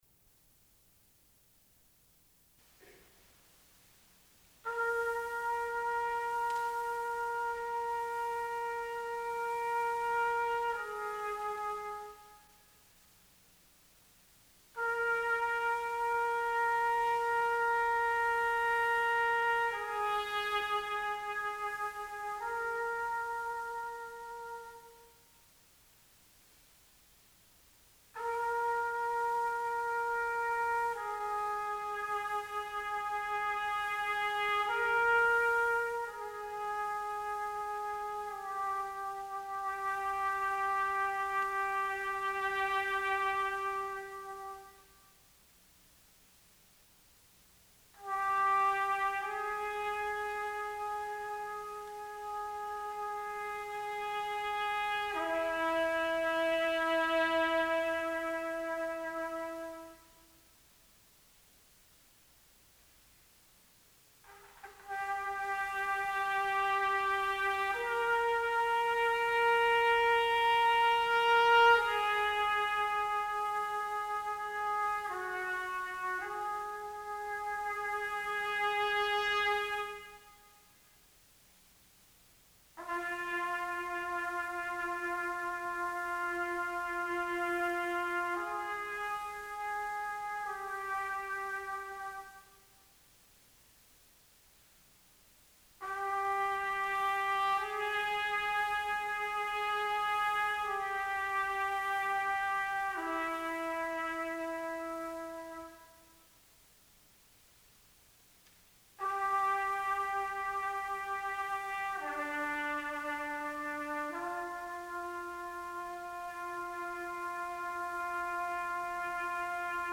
Sound recording of composition for two trumpets, percussion, SATB chorus by Dr. Eddie Jacobs - ECU Digital Collections